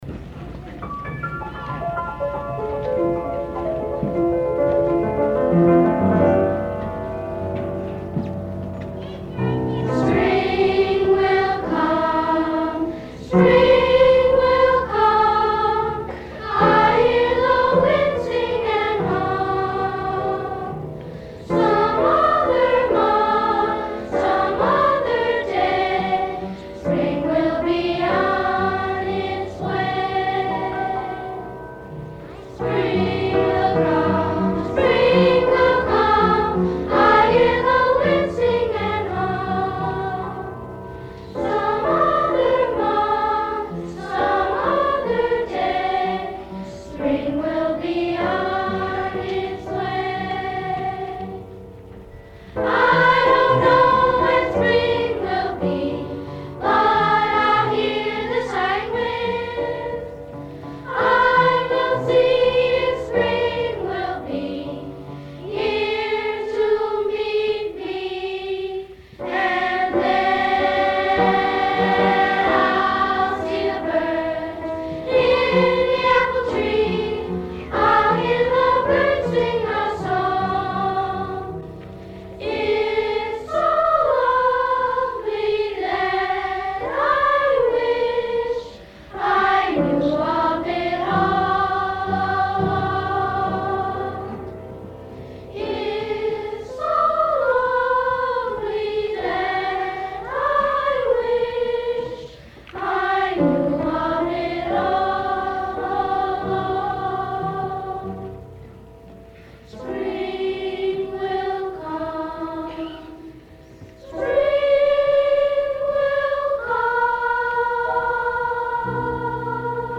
Original Choir Version